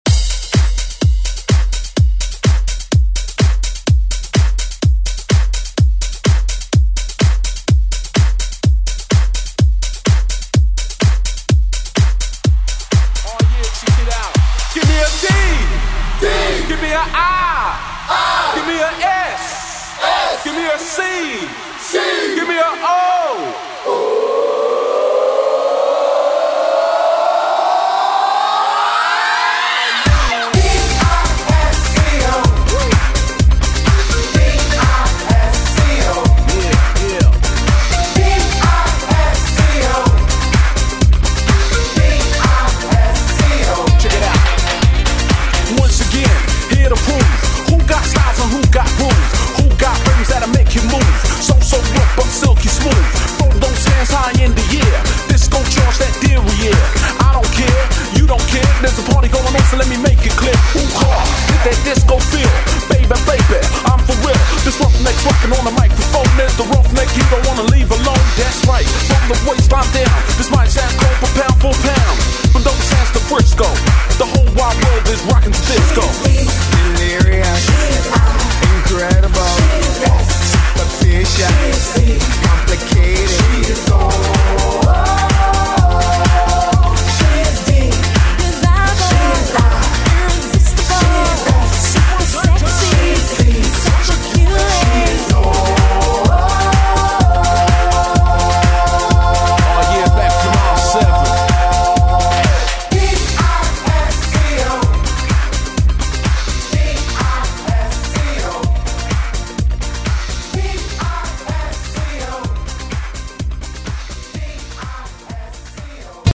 Electronic Pop RnB Funk Soul Music
Extended Intro Outro
100 bpm
Genres: 90's , R & B